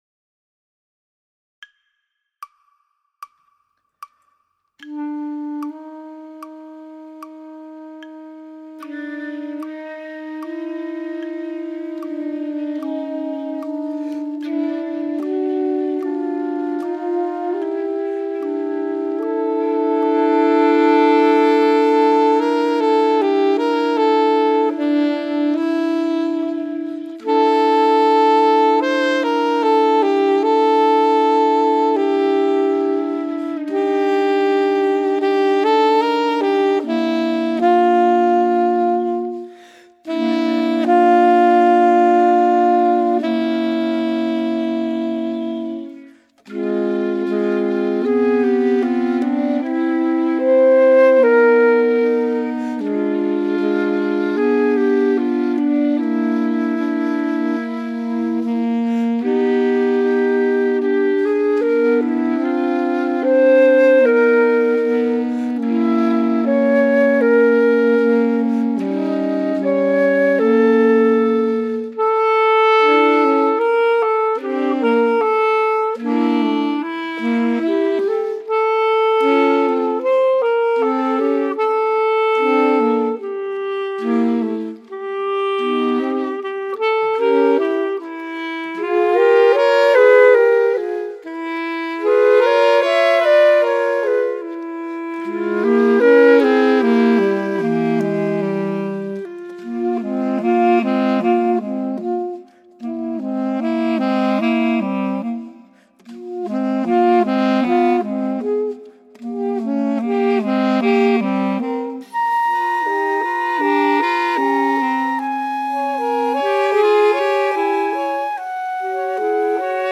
Playalong Starship Serenade ohne 4. Stimme
Starship-Serenade-ohne-4.-Stimme.mp3